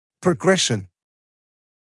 [prə’greʃn][прэ’грэшн]прогрессирование, развитие (пат.состояния)